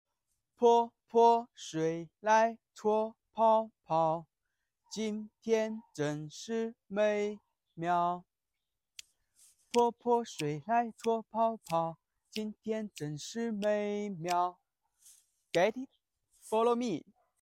dancing song